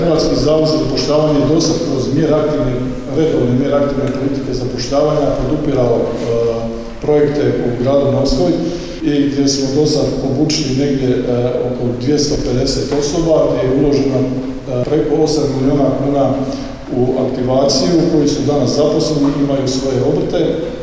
Više od 39 milijuna eura uložila je Vlada RH i resorno ministarstvo za provedbu programa digitalne edukacije više od 30 tisuća osoba, istaknuo je ravnatelj HZZ-a Ante Lončar